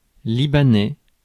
Ääntäminen
Ääntäminen France: IPA: [li.ba.nɛ] Haettu sana löytyi näillä lähdekielillä: ranska Käännös 1. ливански {m} Suku: m . Määritelmät Adjektiivit Du Liban.